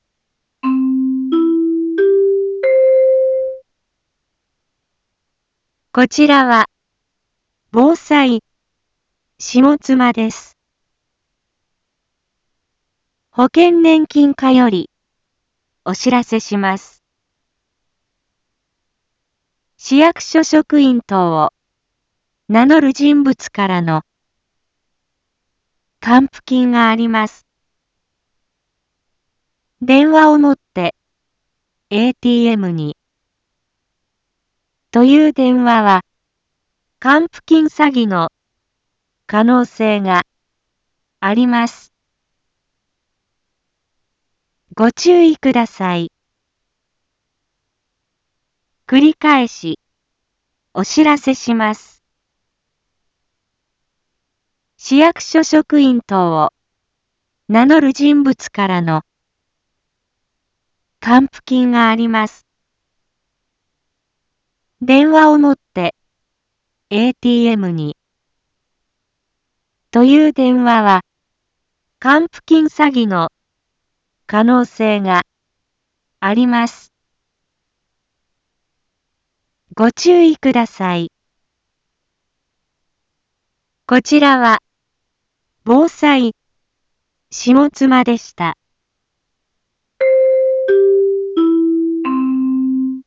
一般放送情報
Back Home 一般放送情報 音声放送 再生 一般放送情報 登録日時：2022-02-15 10:01:37 タイトル：還付金詐欺にご注意を インフォメーション：こちらは、防災下妻です。